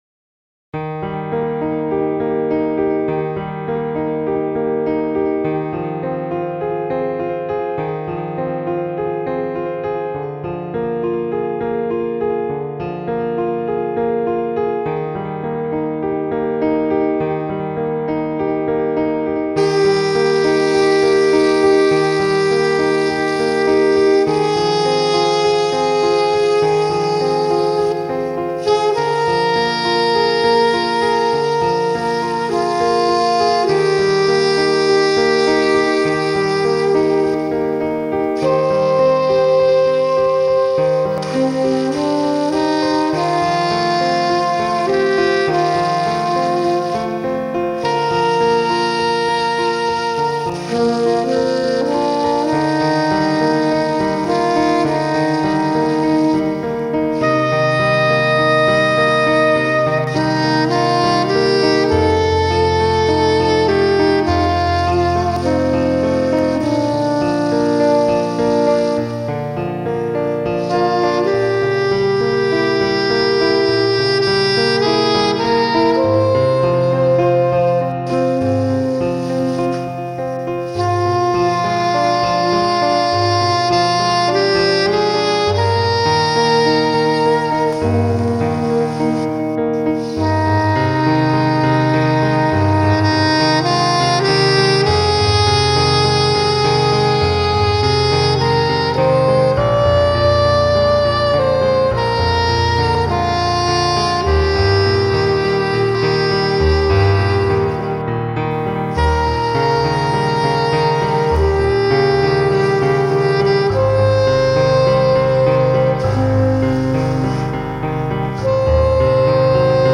Soprano Saxophone